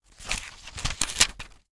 page-flip-17.ogg